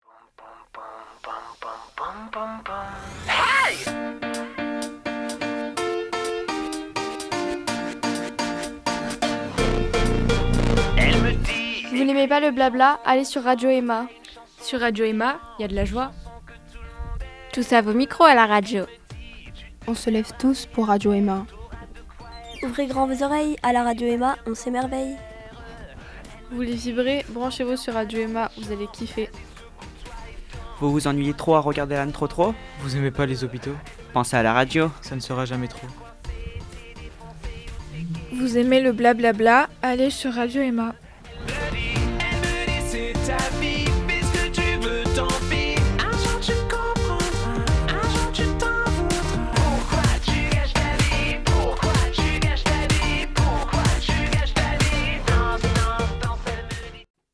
Pour la Journée Mondiale de la Radio 2026, les ados délivrent un message en direct de Radio EMA.